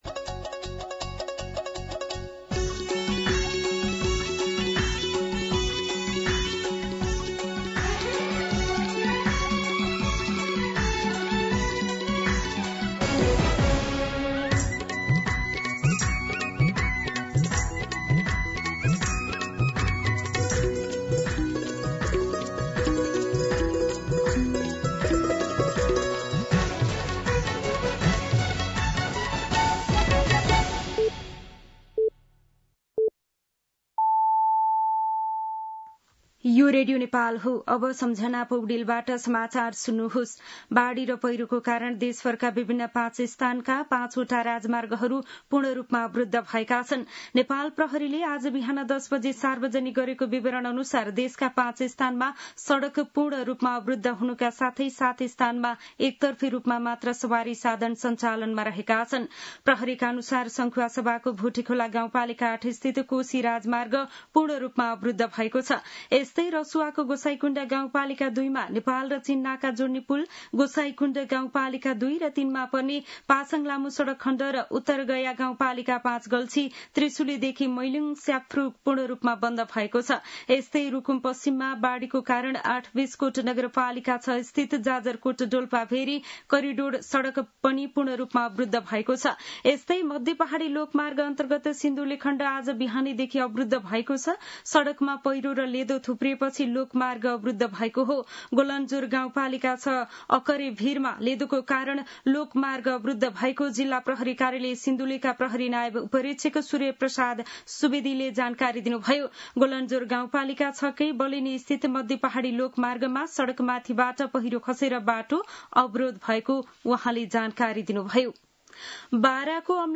मध्यान्ह १२ बजेको नेपाली समाचार : १७ साउन , २०८२
12-pm-Nepali-News-1.mp3